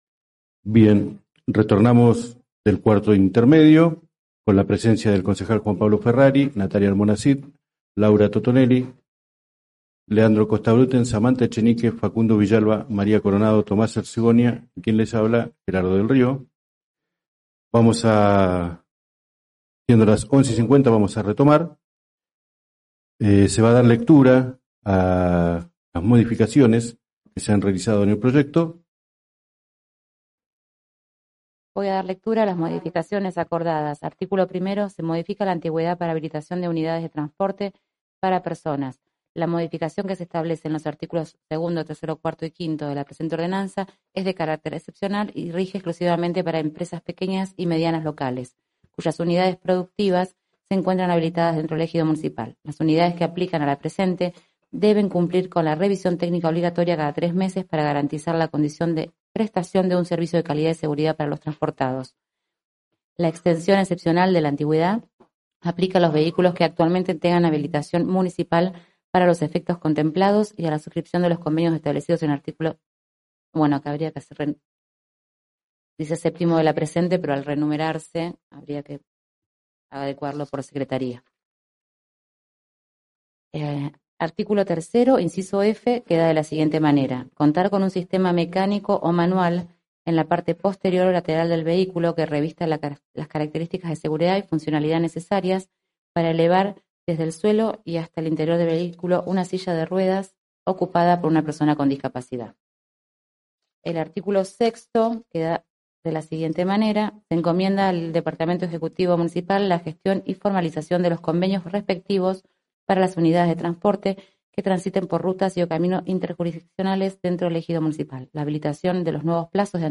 Carácter de la Sesión: Ordinaria. Lugar de realización : Sala Francisco P. Moreno, Concejo Municipal, San Carlos de Bariloche, Provincia de Río Negro, República Argentina.